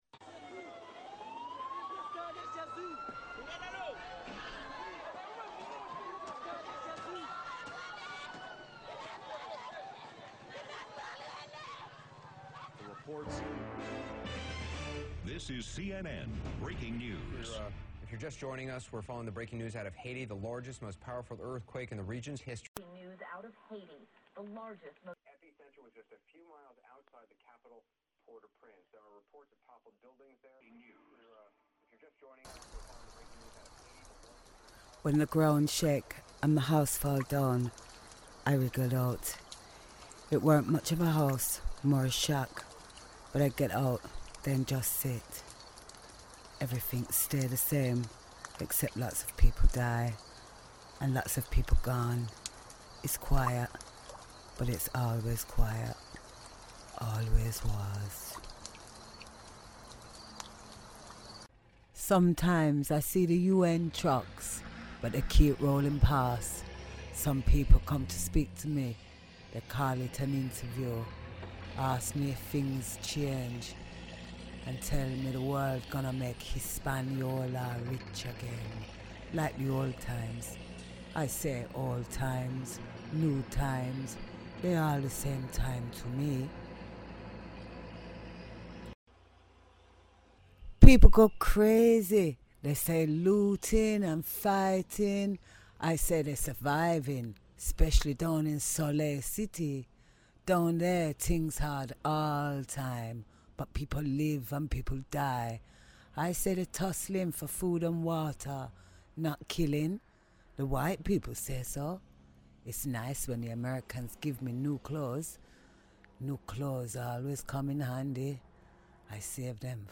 I love plays and this is the first play I ever wrote and recorded. It is about the earthquake in Haiti and a woman's reaction and thoughts